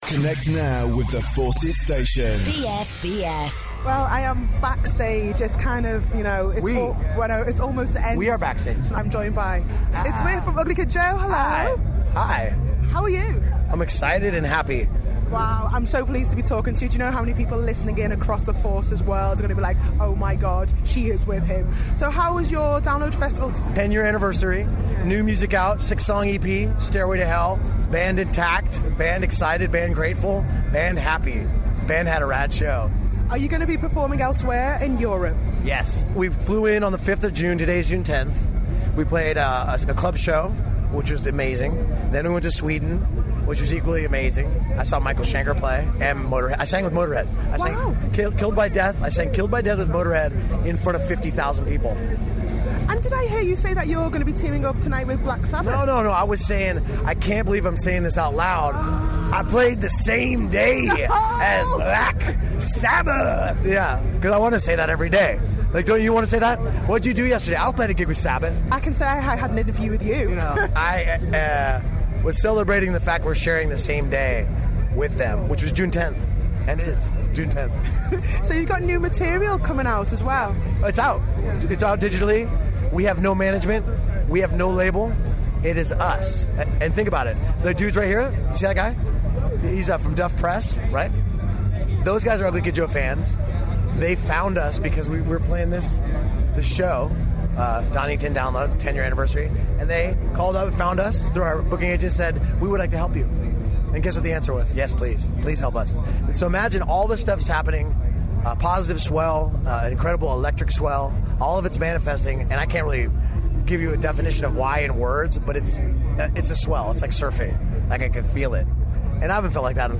Ugly Kid Joe talk to BFBS Rocks